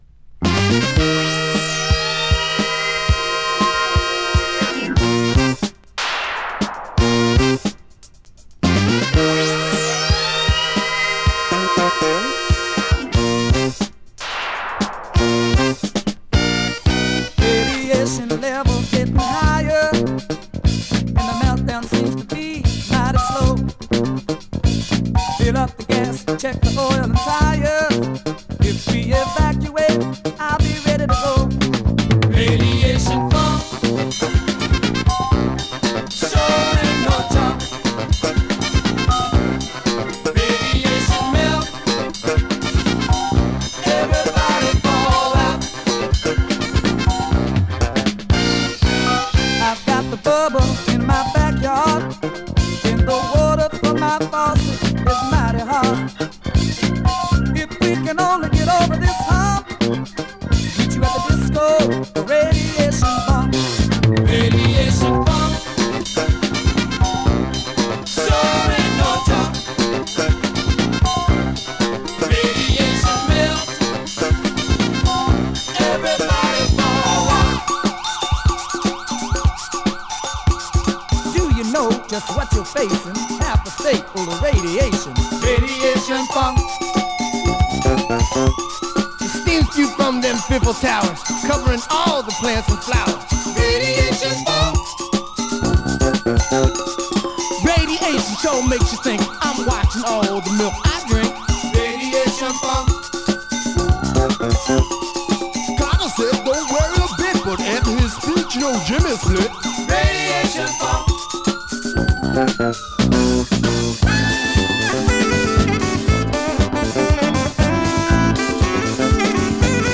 Funk/Soul　ソウル・ファンクバンド
※実物の試聴音源を再生状態の目安にお役立てください。